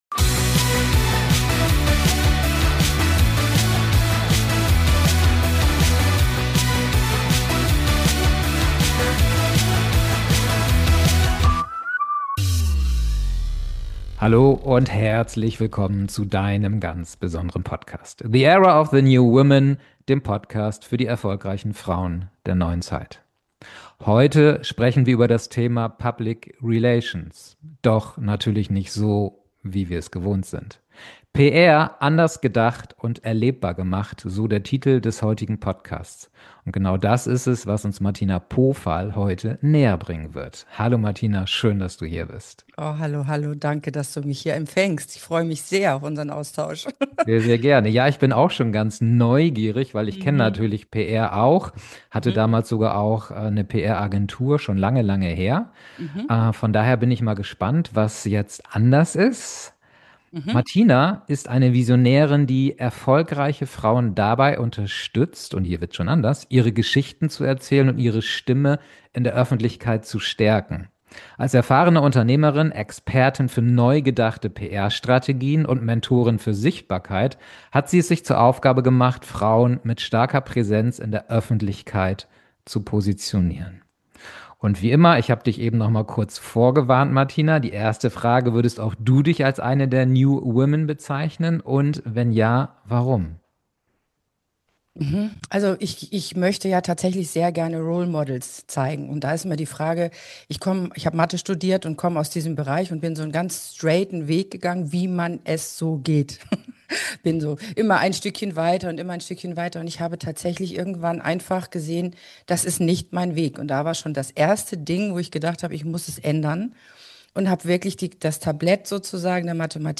Wir sprechen über Echtheit, Mut, neue Bühnen, Netzwerke, Awards, ungewöhnliche Aktionen, das Loslassen alter Masken, den Umgang mit Sicherheit – und warum Authentizität die stärkste Form moderner PR ist. Ein Gespräch voller Inspiration, echter Beispiele, überraschender Impulse und weiblicher Kraft.